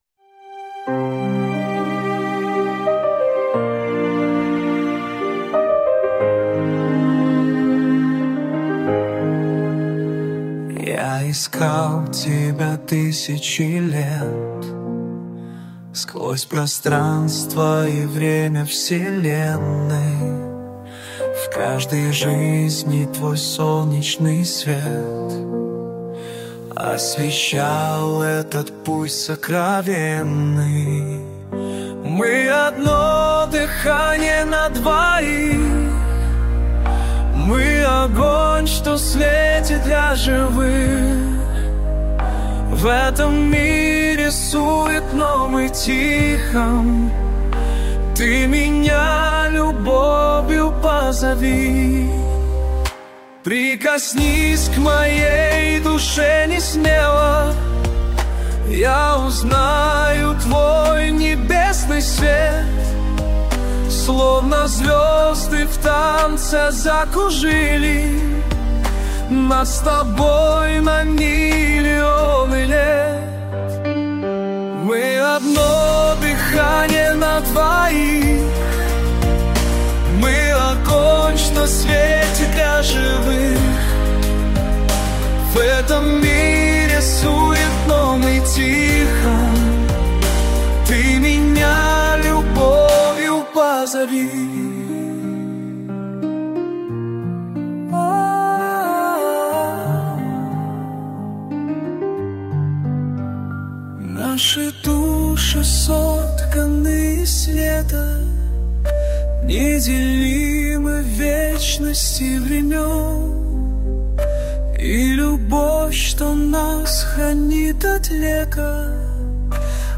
Жанр: Ambient